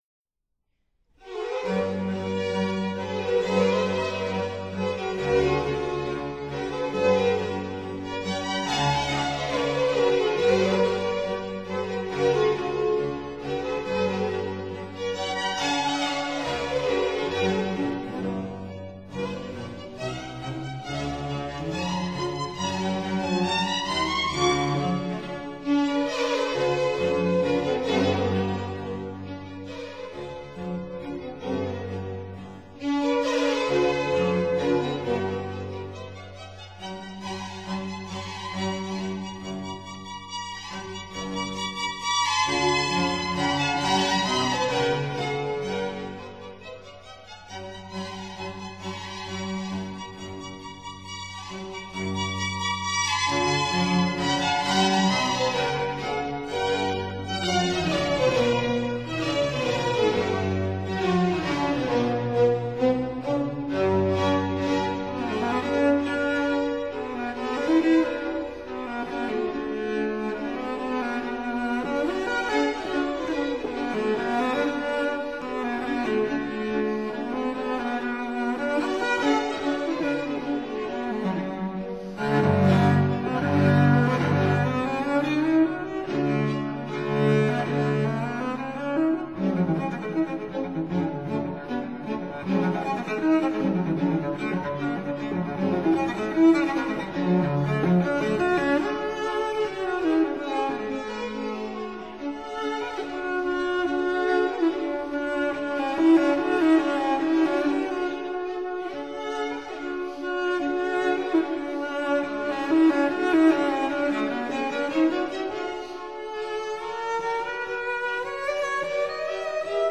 (Period Instruments)